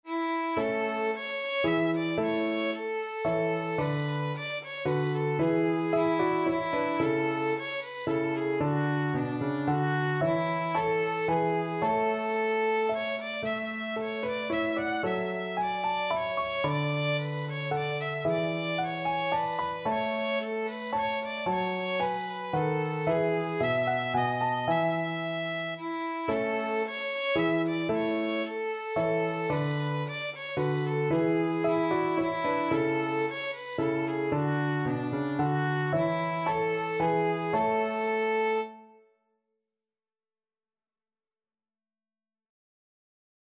Violin
Traditional Music of unknown author.
A major (Sounding Pitch) (View more A major Music for Violin )
3/4 (View more 3/4 Music)
Moderately Fast ( = c. 112)
Classical (View more Classical Violin Music)